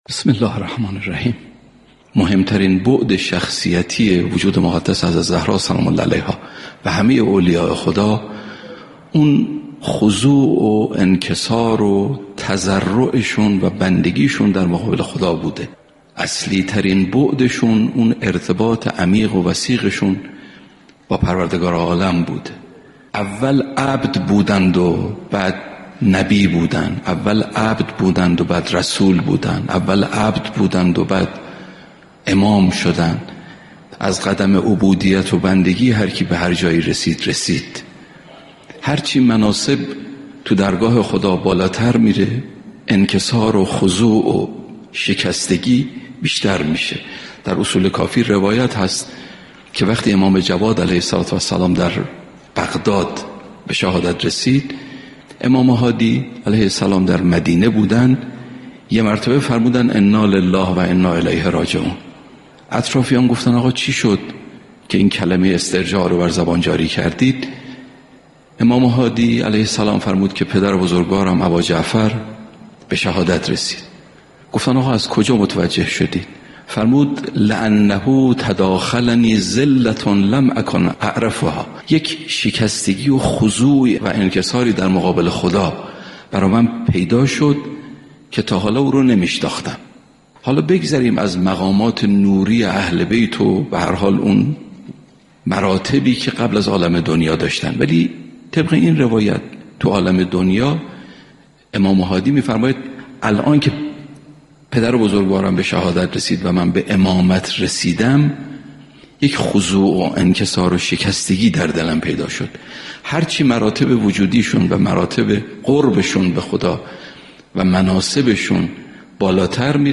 صوت سخنرانی مذهبی و اخلاقی مهم‌ترین بعد شخصیتی وجود حضرت زهرا (س) و همه اولیای خدا، خضوع و انکسار و بندگی در مقابل خداوند می‌باشد. اصلی‌ترین ویژگی اولیای خدا، ارتباط عمیق و وثیق با پروردگار عالم بوده است.